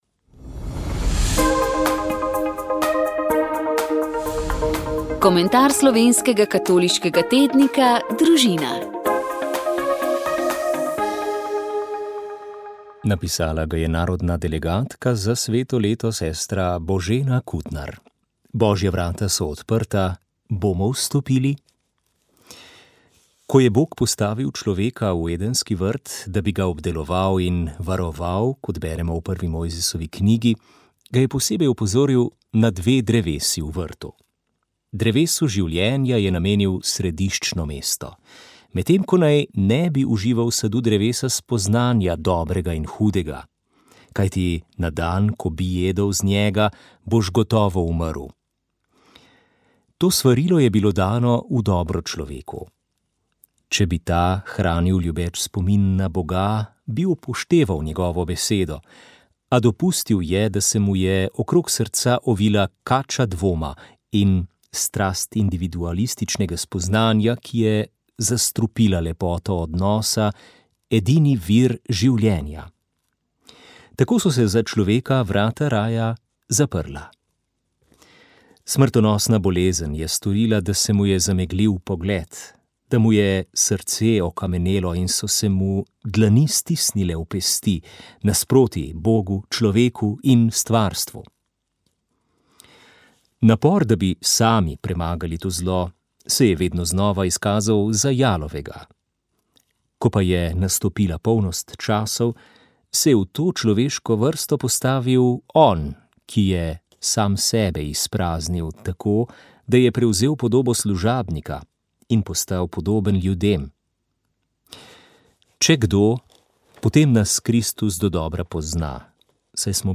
Tamburaši iz Beltincev, ki so lani praznovali 100 let tamburaštva, pa vztrajajo v tradiciji igranja na tako imenovan Fárkašev, oziroma dvoglasni kvintni sistem, ki je drugod v Sloveniji že skorajda zamrl.
V oddaji iz cikla Pevci zapojte, godci zagodte smo predvajali nekaj odlomkov z lanskoletnega terenskega snemanja